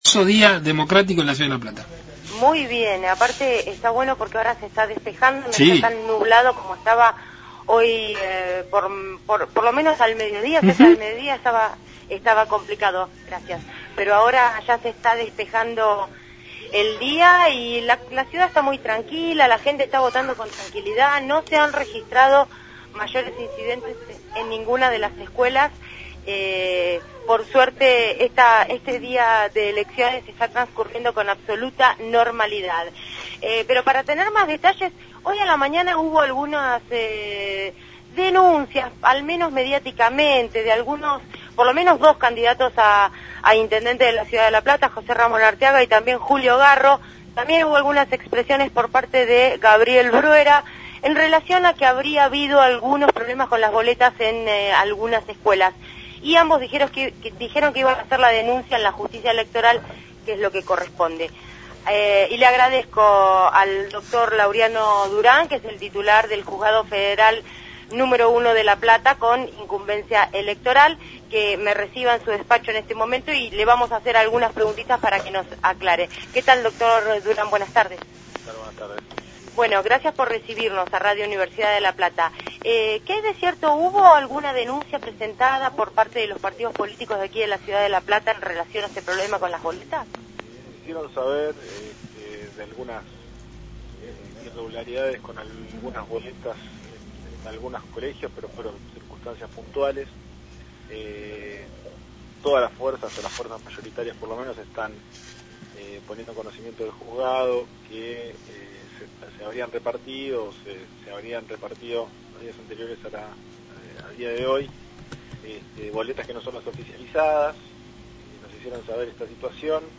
columna diaria